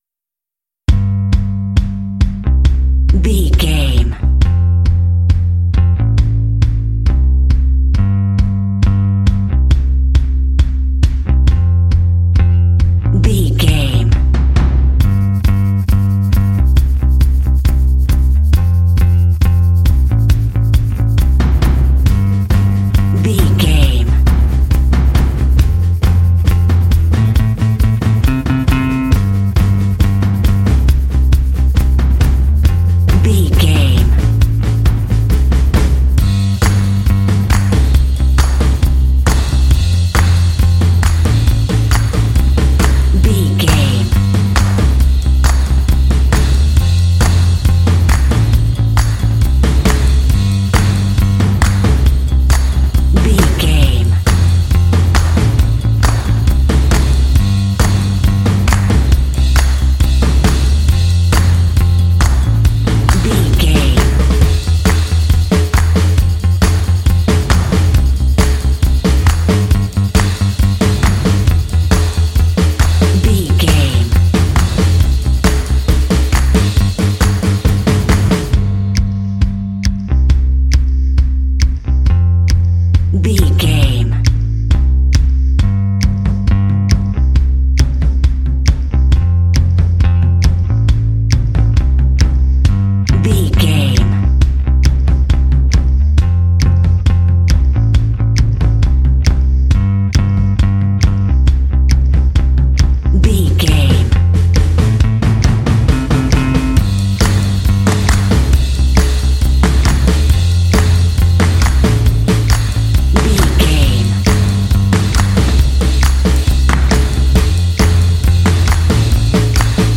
Fun and cheerful indie track with bells and “hey” shots.
Uplifting
Ionian/Major
cheerful/happy
playful
bass guitar
drums
percussion
indie
alternative rock
contemporary underscore